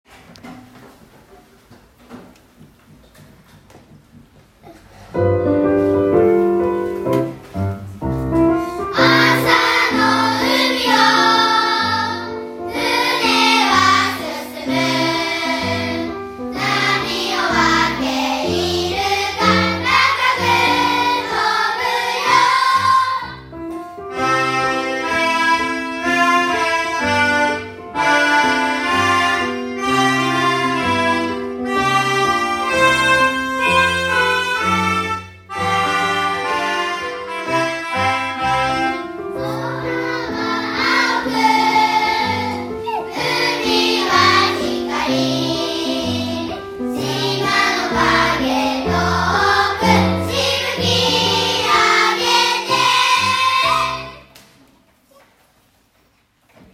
0606♪3年「海風切って」歌声